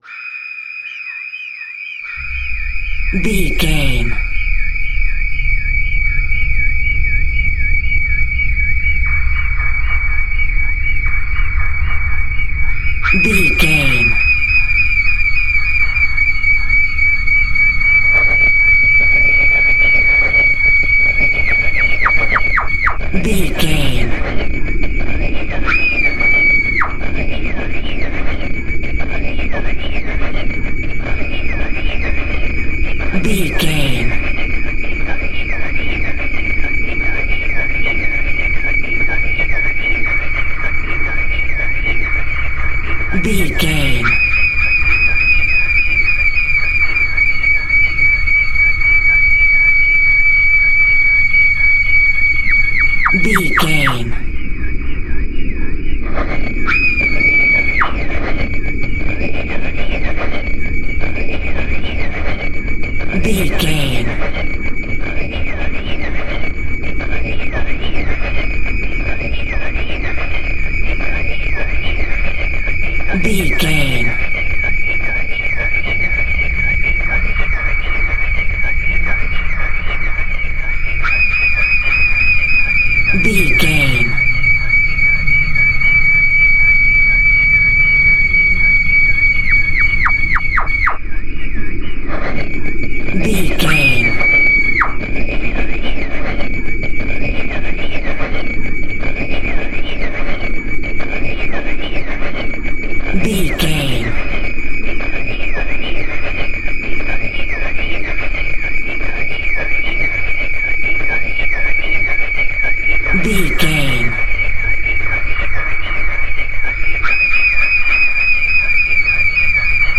Atonal
ominous
dark
suspense
haunting
eerie
creepy
synth
keyboards
ambience
pads
eletronic